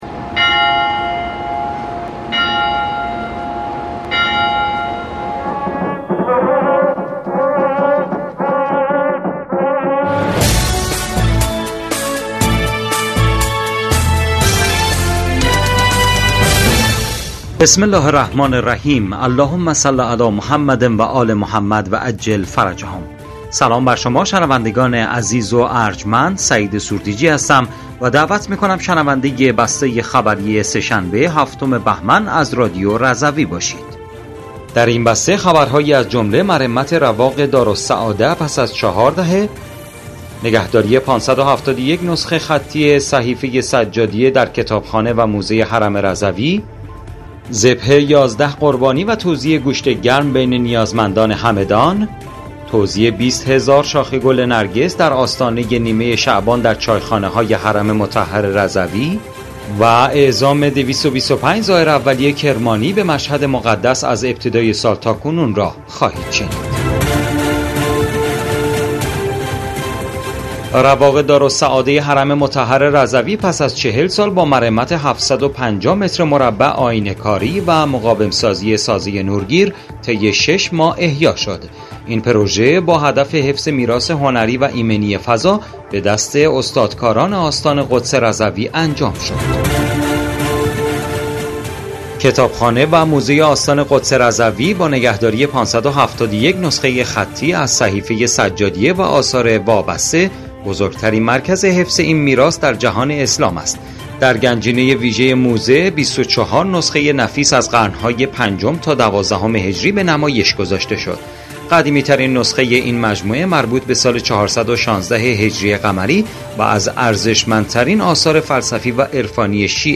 بسته خبری ۷ بهمن ۱۴۰۴ رادیو رضوی؛